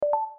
snapei-beep-scan-2.DAFKkGUL.mp3